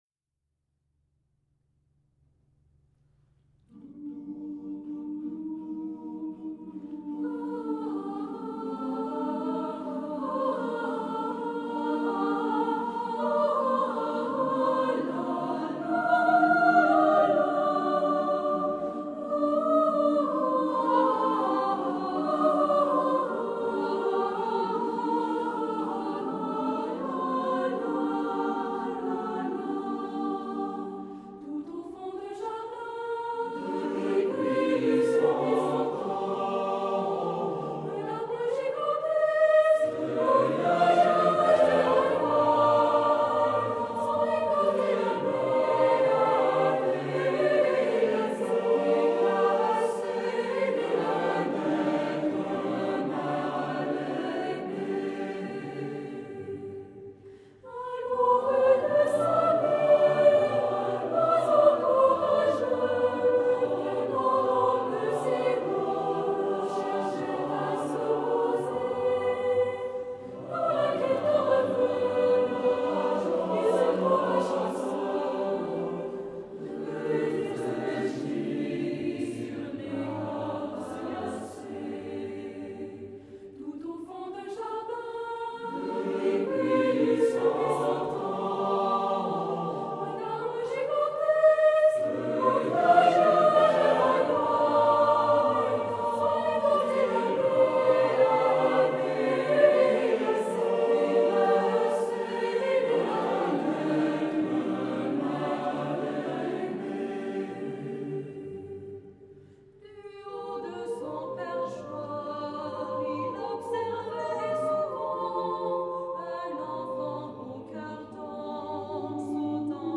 oeuvre pour choeur mixte SATB a cappella